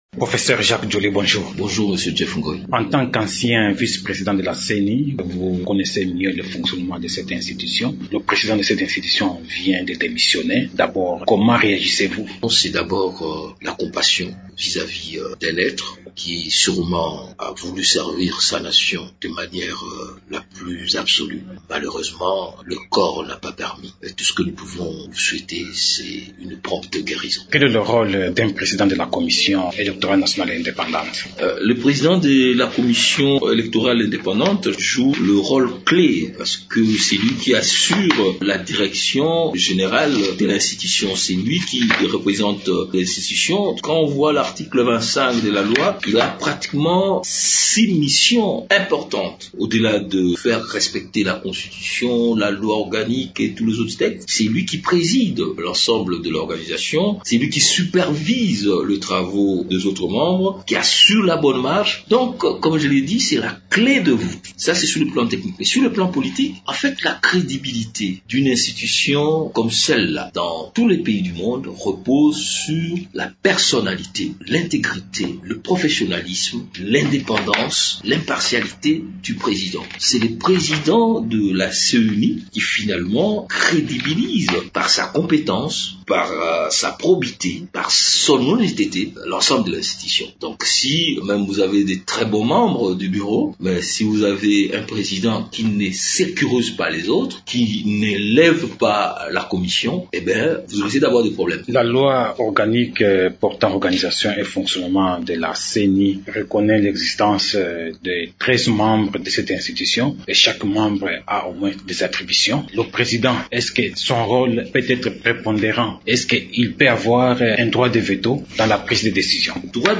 Le professeur  Jacques Djoli est au micro